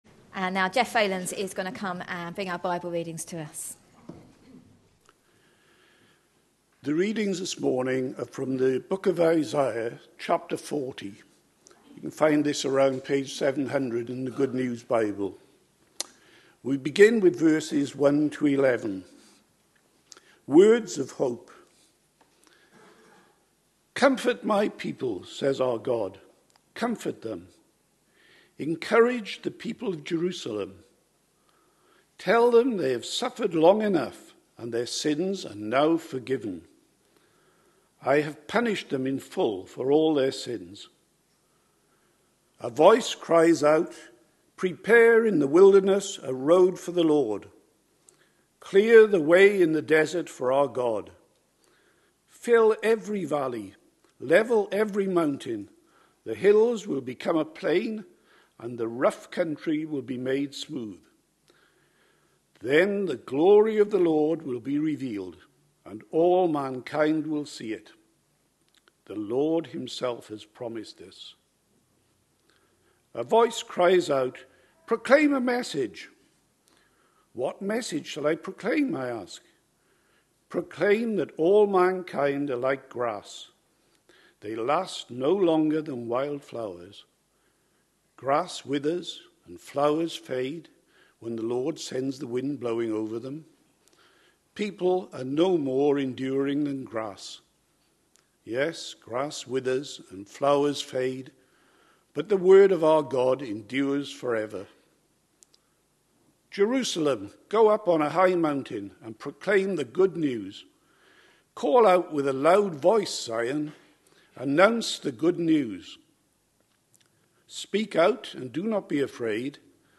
A sermon preached on 8th December, 2013.